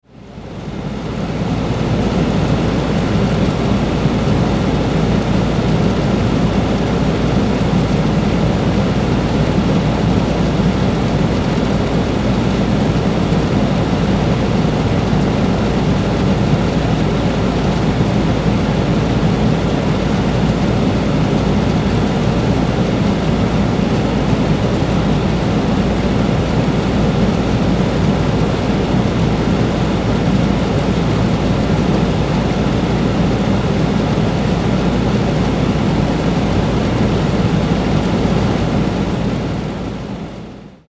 خوابیدن بچه white voice
دسته : موسیقی ملل
download-white-voice(2).mp3